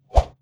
Close Combat Swing Sound 65.wav